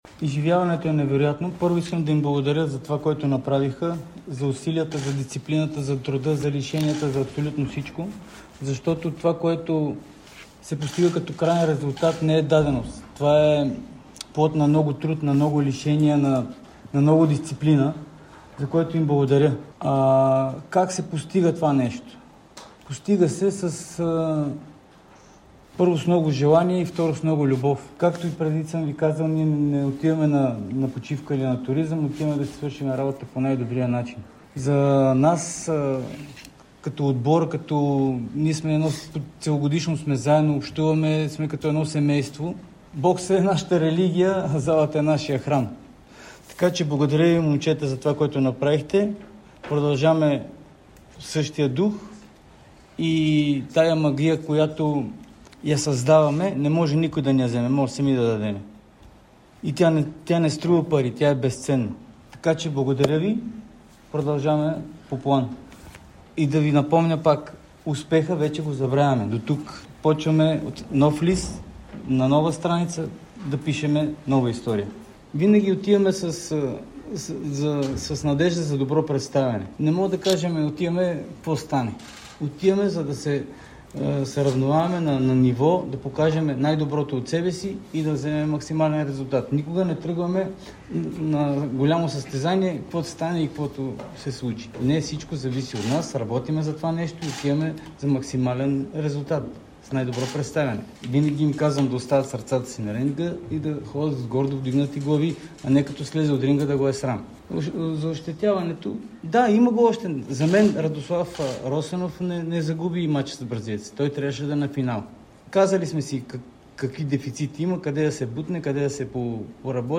дадоха пресконференция в пресклуб „България“.
В емоционално обръщение